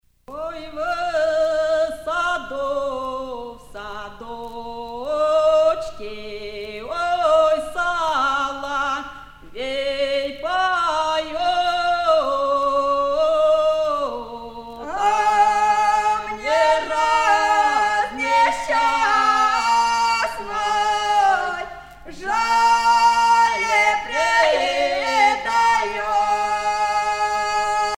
Russian folksongs sung by the Don Cossacks
Pièce musicale éditée